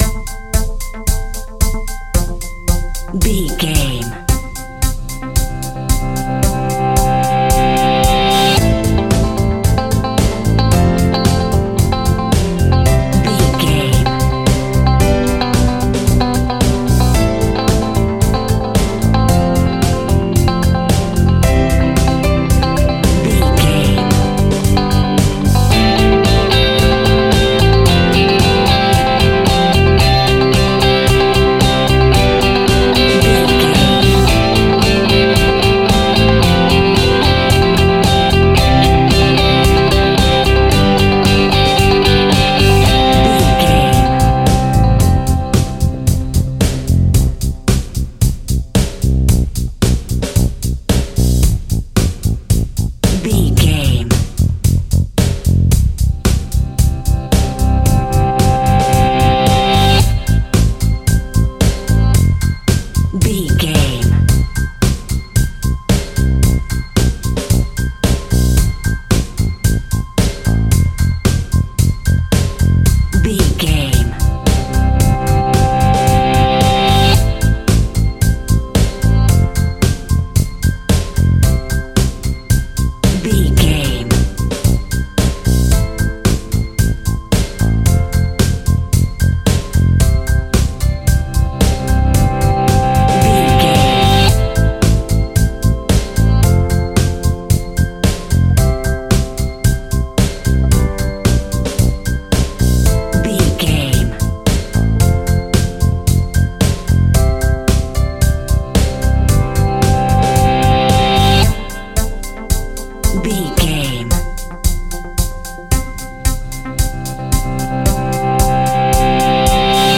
Ionian/Major
groovy
powerful
organ
drums
bass guitar
electric guitar
piano